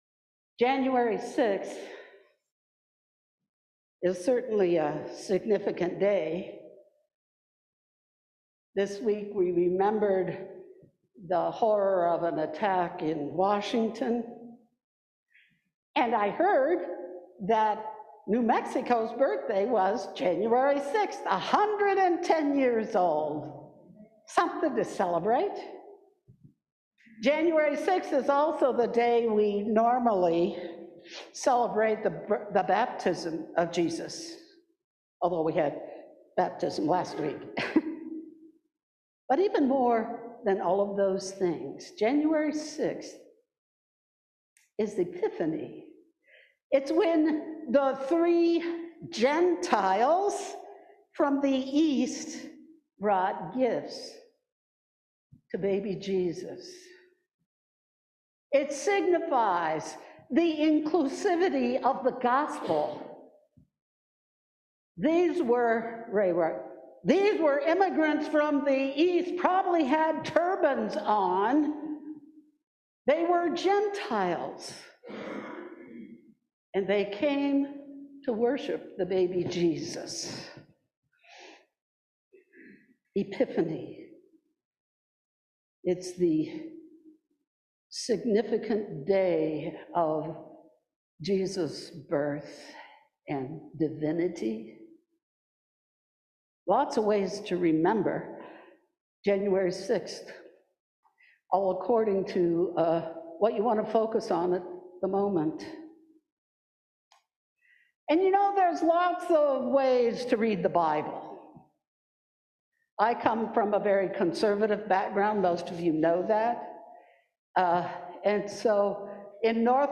John 2:1-11 Service Type: Sunday Morning « Jesus says “Come and See” Jesus cleanses the temple »